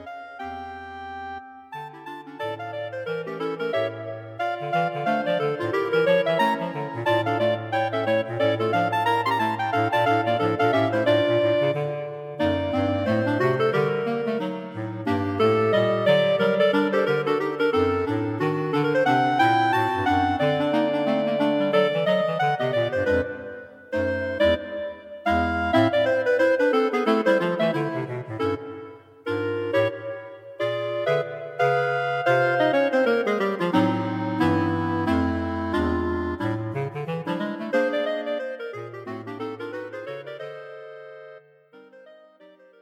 Clarinet Quartet for Concert performance